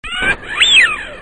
Le Canard colvert